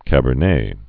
(kăbər-nā)